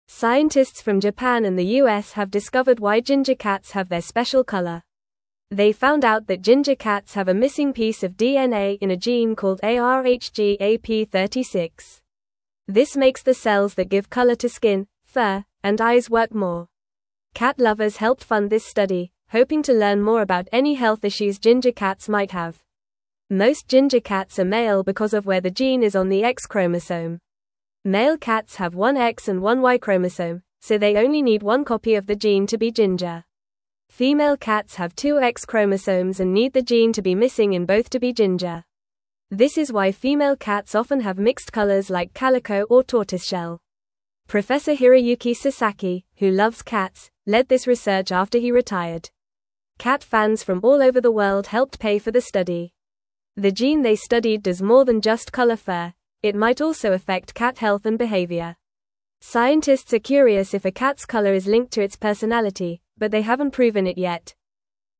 Fast
English-Newsroom-Lower-Intermediate-FAST-Reading-Why-Ginger-Cats-Are-Mostly-Boys-and-Orange.mp3